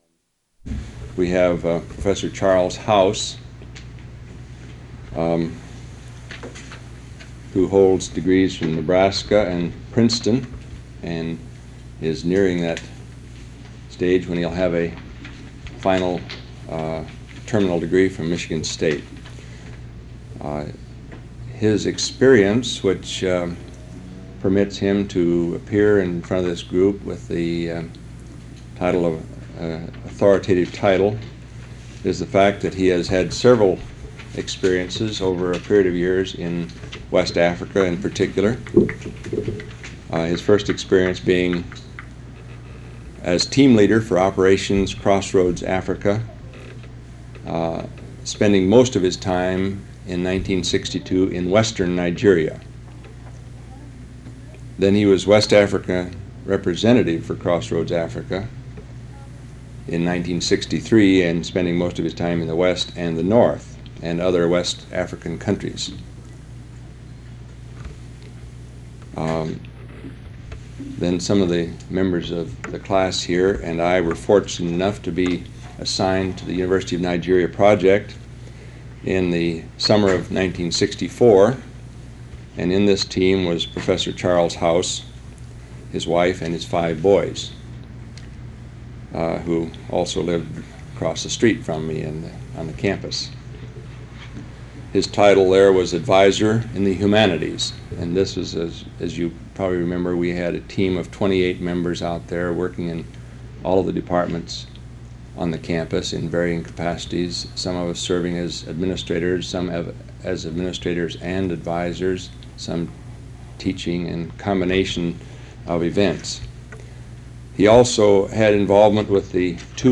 Lecture, 1968
Original Format: Open reel audio tape